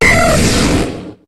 Cri de Raikou dans Pokémon HOME.